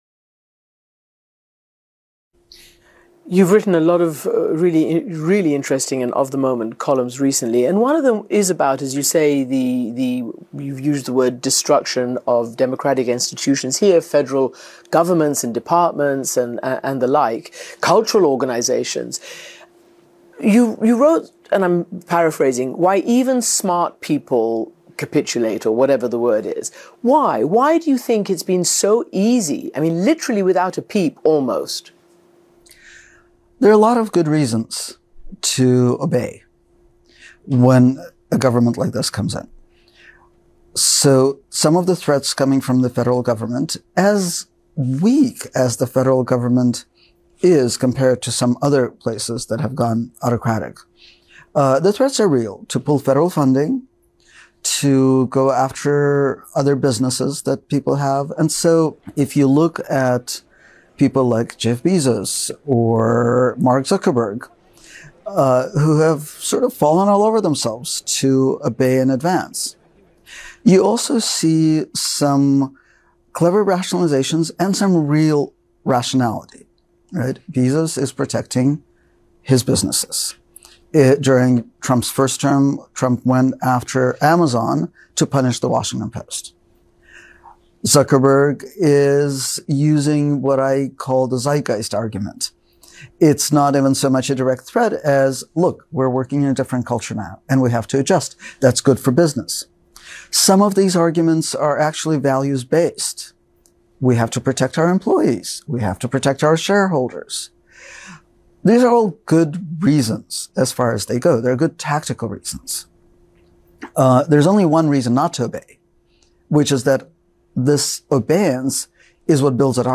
M. Gessen interviewed by Christiane Amanpour: a lot of good reasons why people obey before they need to.mp3